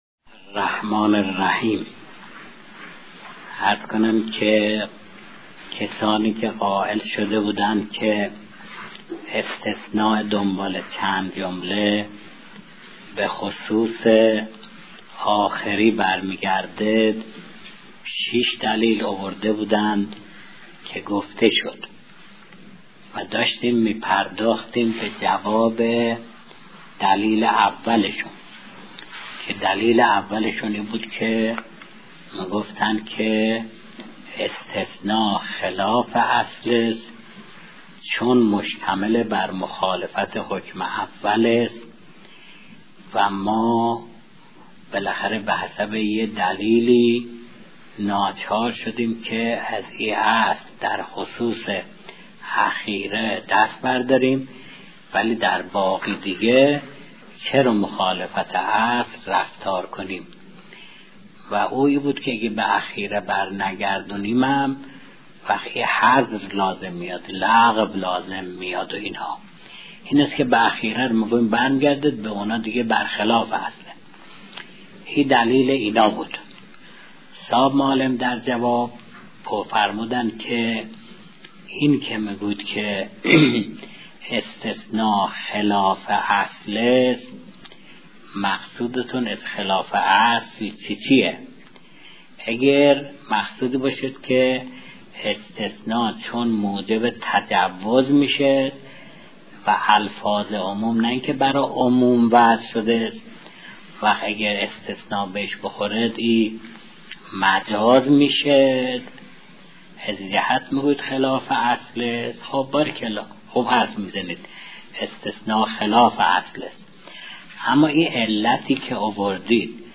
معالم الاصول | مرجع دانلود دروس صوتی حوزه علمیه دفتر تبلیغات اسلامی قم- بیان